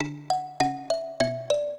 mbira
minuet4-7.wav